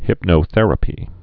(hĭpnō-thĕrə-pē)